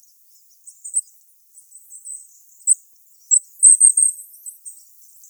Regulus regulus - Goldcrest - Regolo
E 11,2679° - ALTITUDE: 0 m. - VOCALIZATION TYPE: two call types.
Background: Goldcrest calls (see spectrograms).